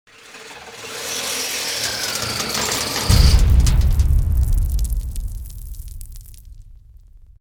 anchor.wav